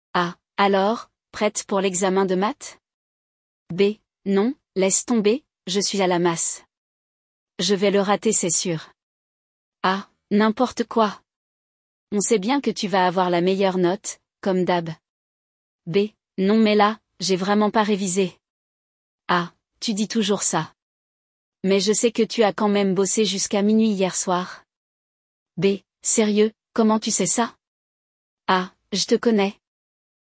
No episódio de hoje, vamos ouvir um diálogo sobre essa situação. Uma dupla de amigos, Zack e Judith, conversam sobre uma prova de matemática que estão prestes a fazer, mas ela não está tão confiante…